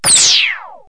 WHOOSH.mp3